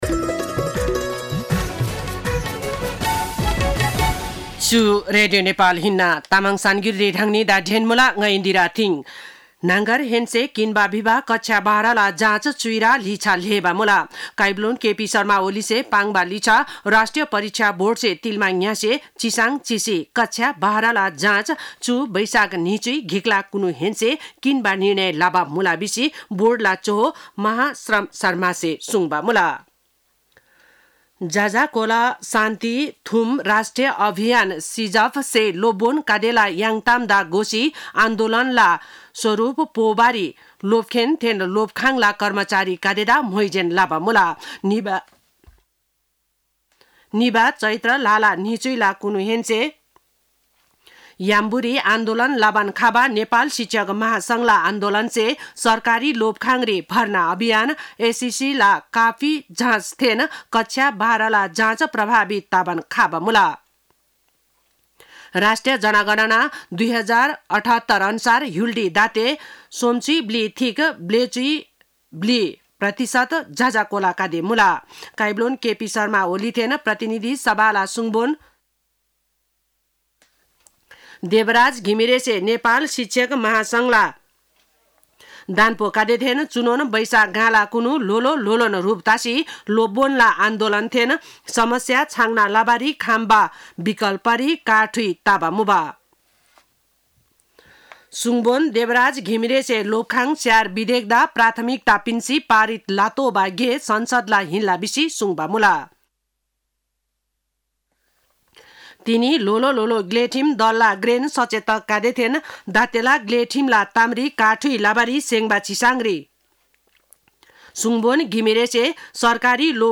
तामाङ भाषाको समाचार : १० वैशाख , २०८२
5.5-pm-tamang-news-1.mp3